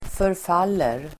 Uttal: [förf'al:er]